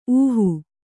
♪ ūhu